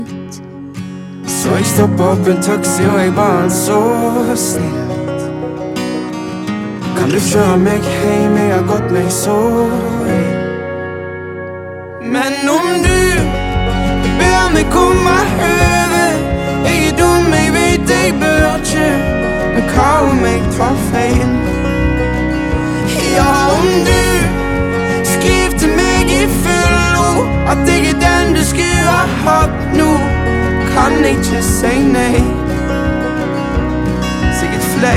Жанр: Кантри